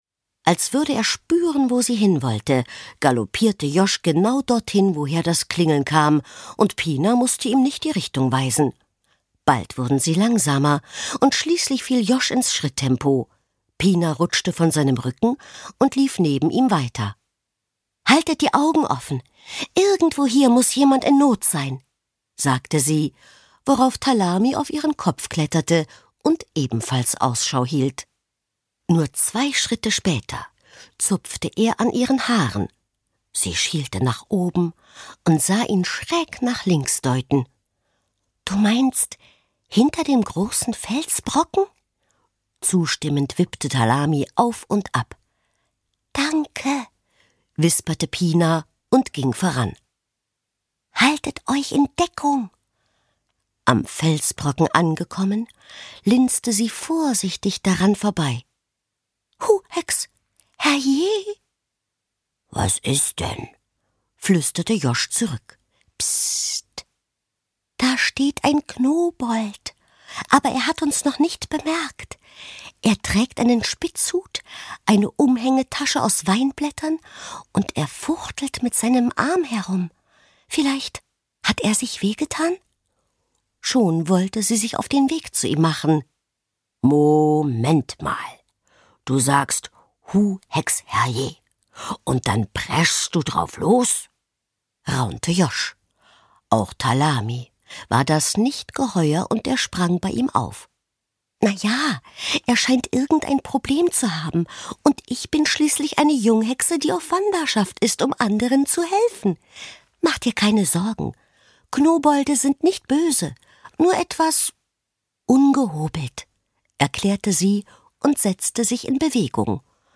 2025 | 1. Auflage, Ungekürzte Ausgabe
• Mit gereimten Zaubersprüchen und ganz viel Fantasie erzählt